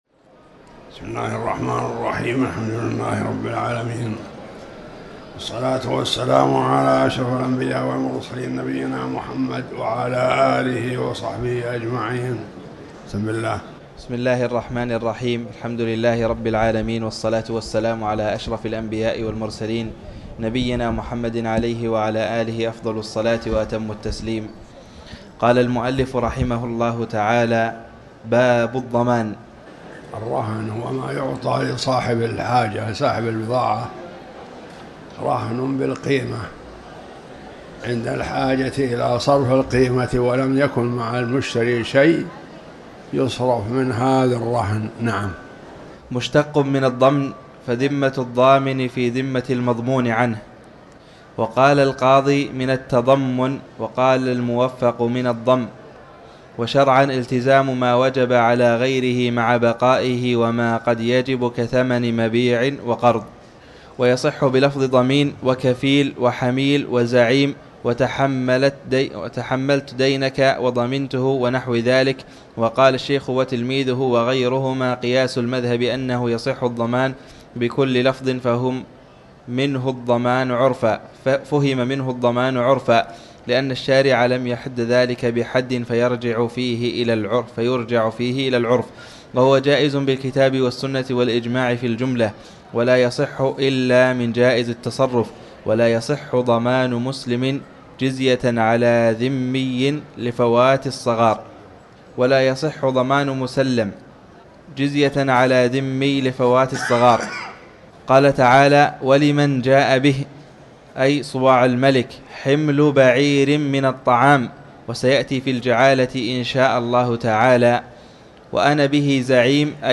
تاريخ النشر ٣٠ ربيع الثاني ١٤٤٠ هـ المكان: المسجد الحرام الشيخ